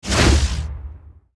archer_skill_spiralkick_swing_a.wav